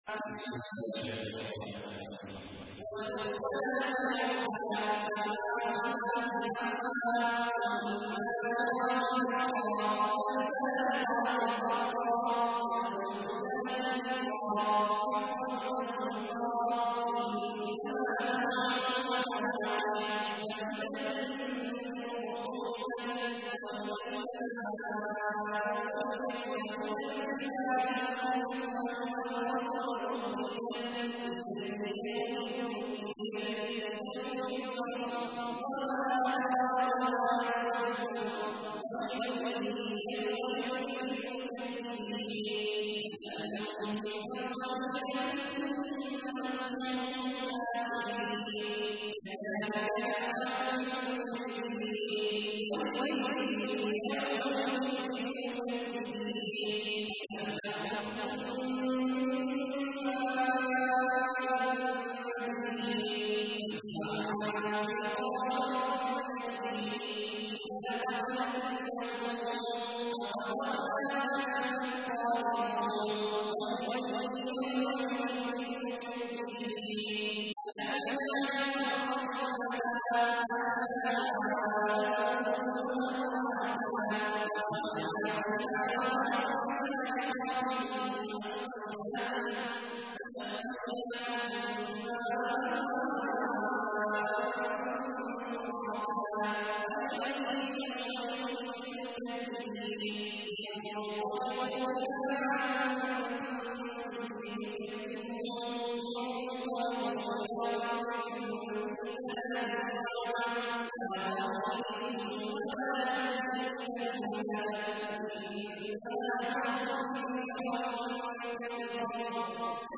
تحميل : 77. سورة المرسلات / القارئ عبد الله عواد الجهني / القرآن الكريم / موقع يا حسين